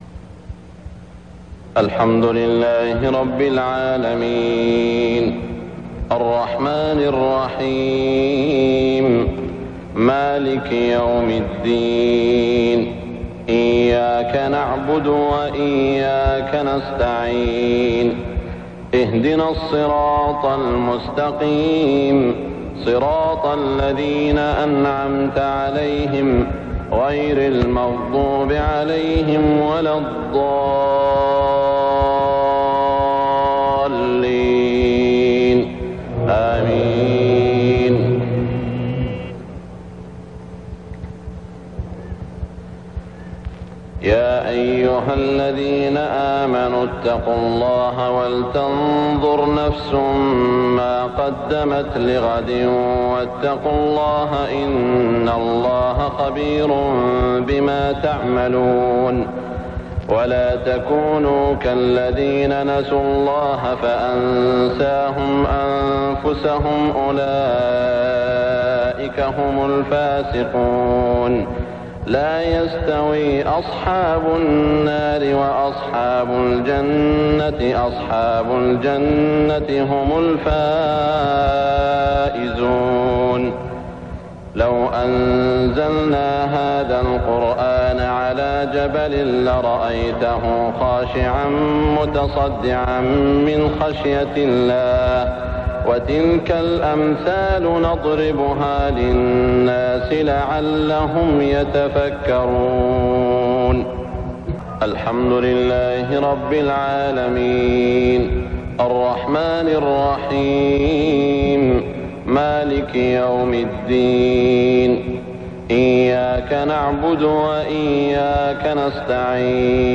صلاة المغرب 1419هـ خواتيم سورة الحشر > 1419 🕋 > الفروض - تلاوات الحرمين